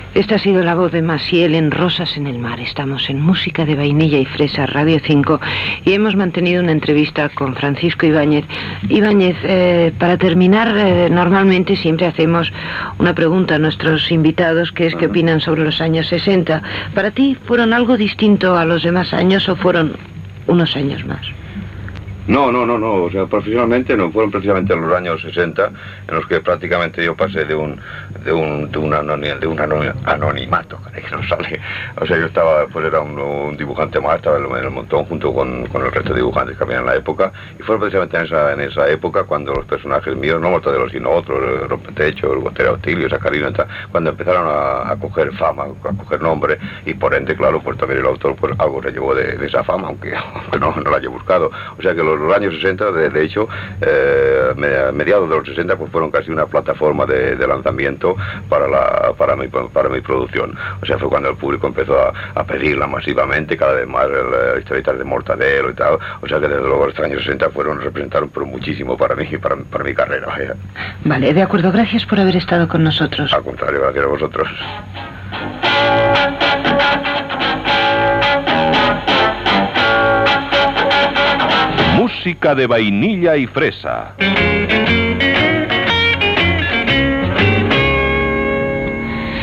Identificació del programa, resposta del dibuixant Francisco Ibáñez sobre els anys 1960 i els seus còmics. Indicatiu del programa.
Cultura